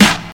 Clean Snare Drum Sound G# Key 127.wav
Royality free steel snare drum sound tuned to the G# note. Loudest frequency: 2037Hz
clean-snare-drum-sound-g-sharp-key-127-4dp.mp3